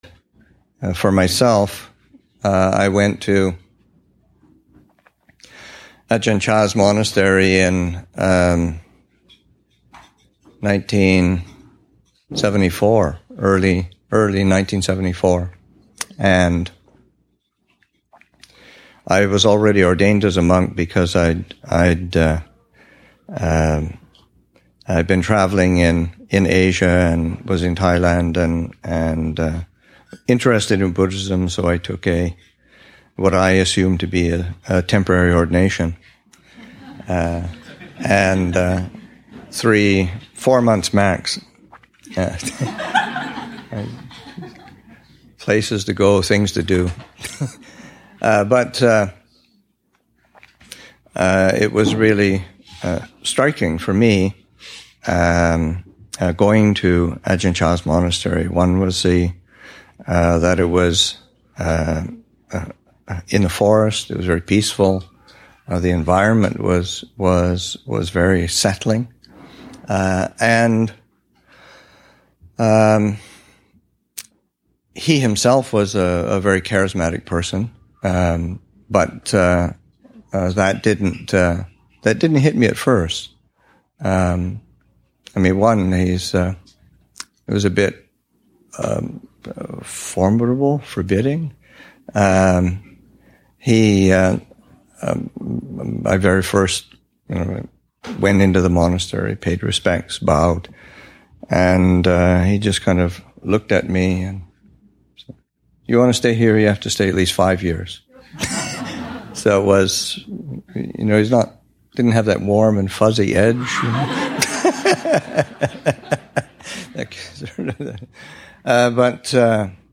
Abhayagiri Anniversary event, Jun. 4, 2016
Abhayagiri Buddhist Monastery in Redwood Valley, California and online
Many people gathered at Abhayagiri on June 4, 2016, to meet old friends, celebrate and share reflections.